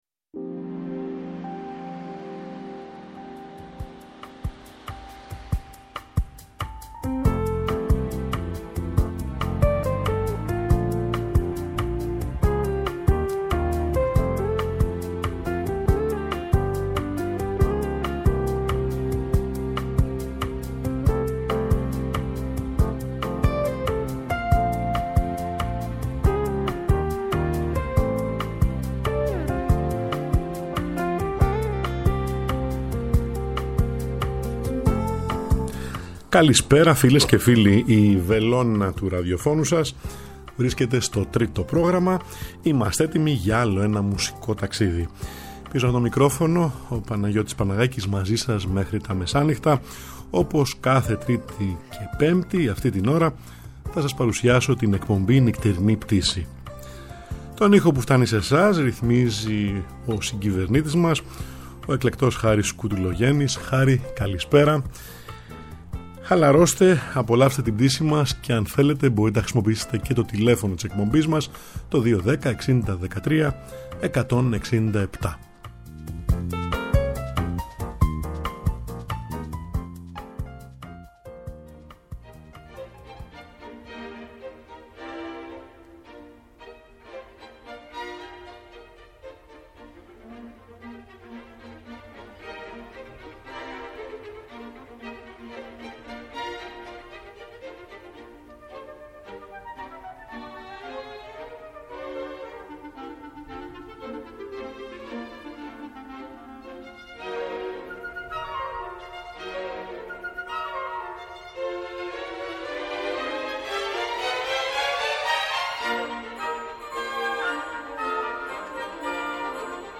Στη «Νυχτερινή Πτήση» που απογειώνεται κάθε Τρίτη & Πέμπτη μία ώρα πριν από τα μεσάνυχτα, στο Τρίτο Πρόγραμμα 90,9 & 95,6 της Ελληνικής Ραδιοφωνίας